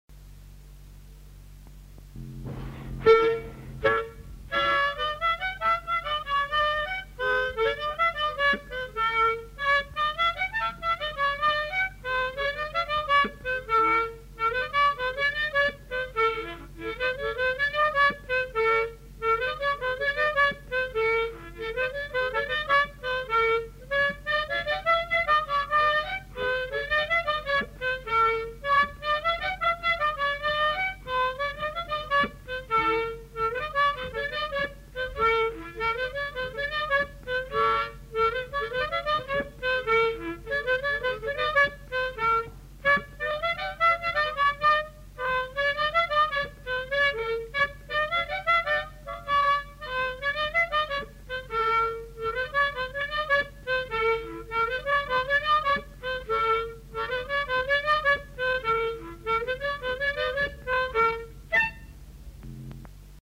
Aire culturelle : Haut-Agenais
Genre : morceau instrumental
Instrument de musique : harmonica
Danse : rondeau